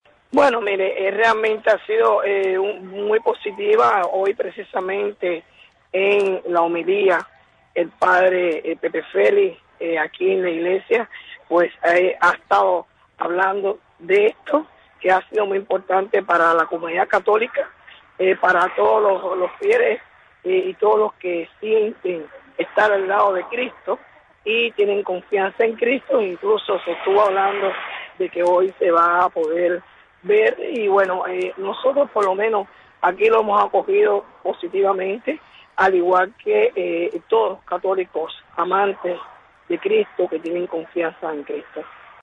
Berta Soler habla de las celebraciones en Cuba